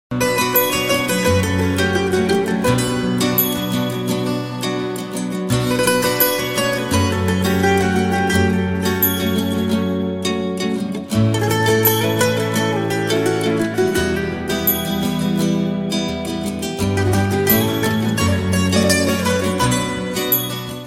زنگ غمگین موبایل بی کلام